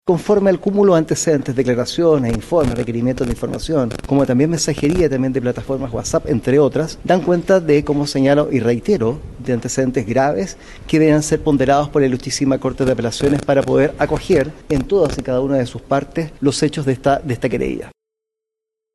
Durante los alegatos, el director de la Unidad Anticorrupción de la Fiscalía Nacional, Eugenio Campos, sostuvo que los antecedentes expuestos cumplen con el estándar de plausibilidad exigido por la ley para acoger una querella de capítulos.